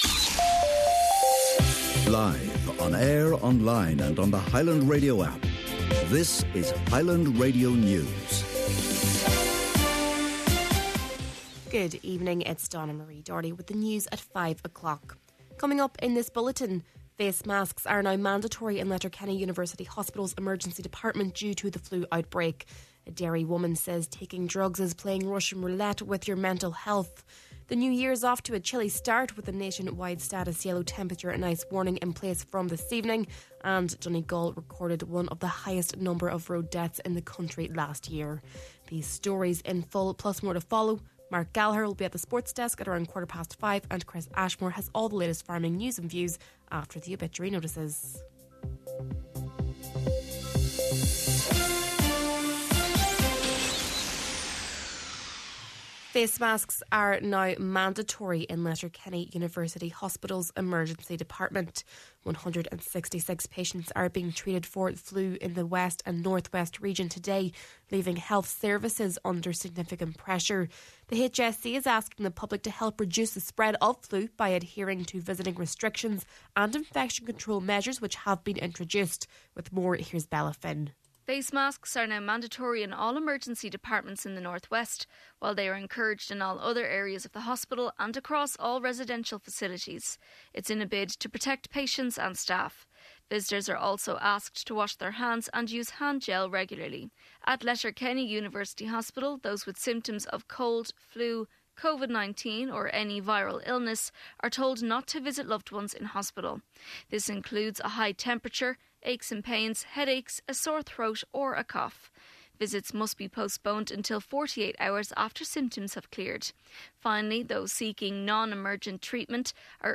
Main Evening News, Sport, Farming News and Obituaries – Thursday, January 2nd